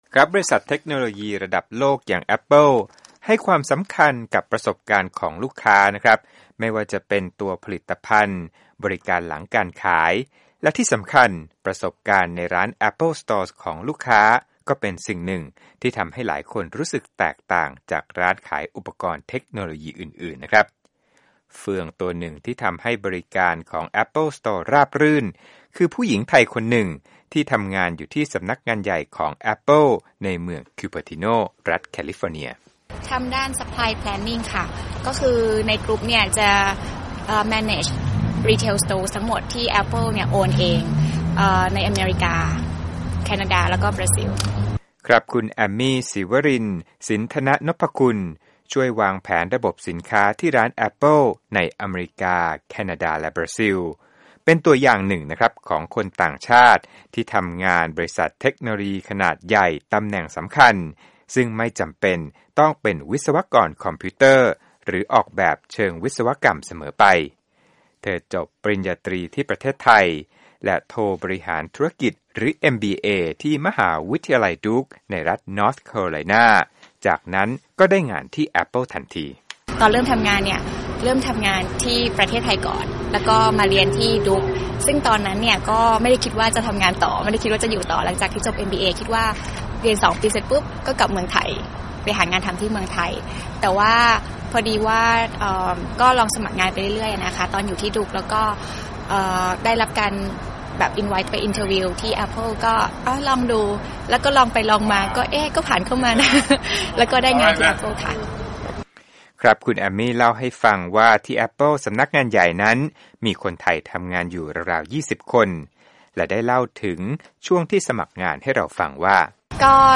Apple Interview